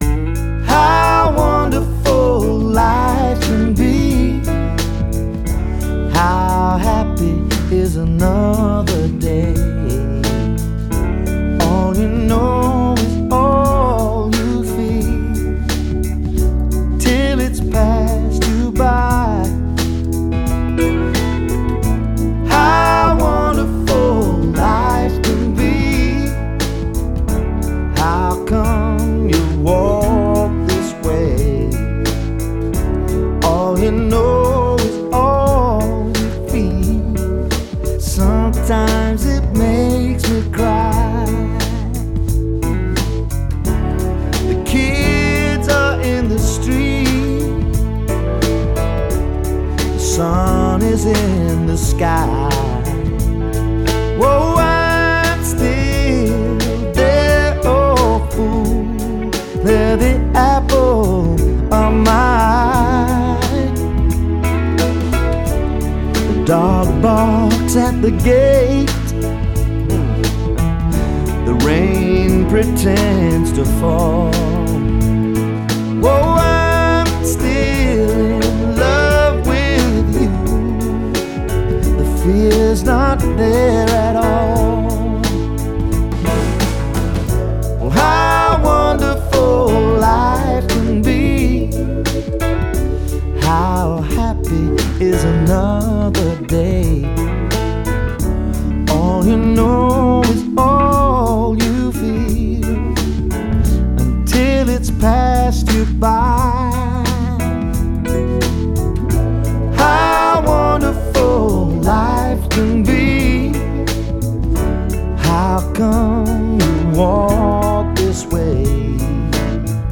Genre: Pop/Rock, Blue-eyed Soul